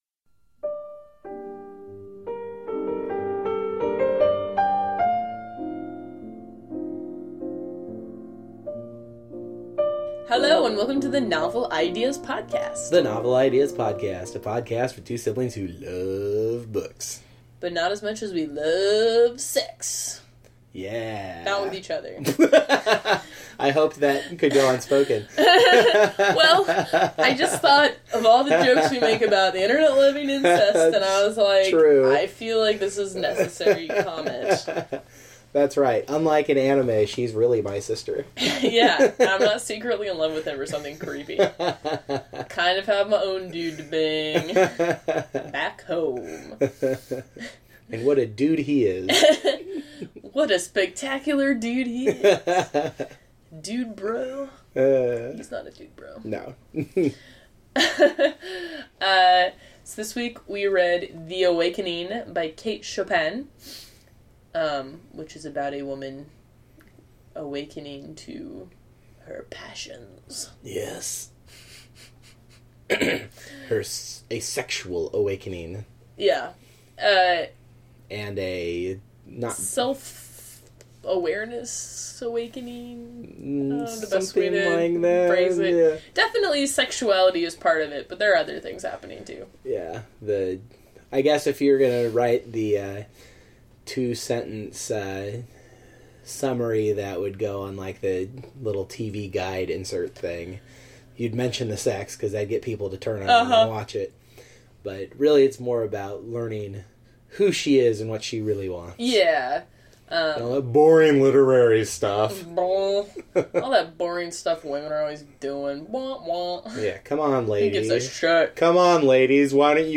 The music bump this week is Frederic Chopin’s Nocture opus 15, number 3 in G Minor, also subtitled “Solitude” for its possibly awakening Edna’s…. awakening, I guess.